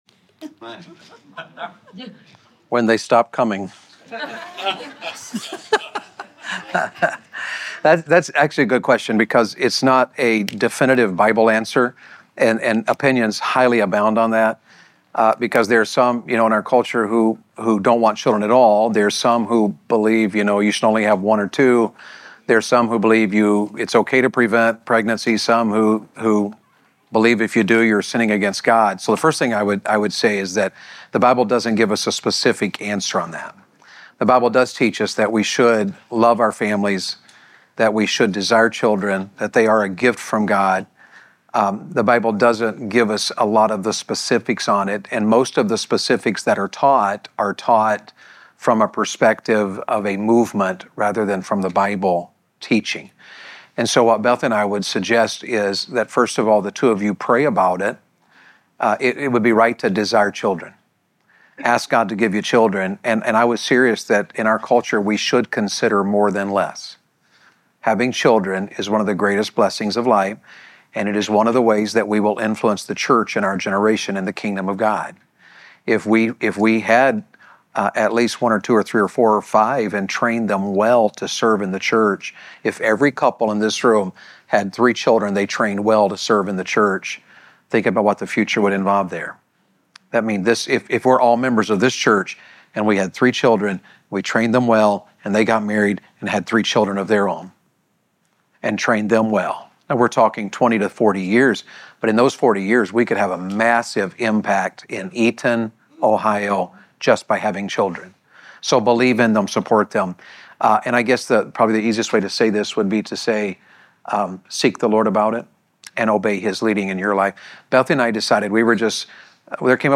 Question & Answers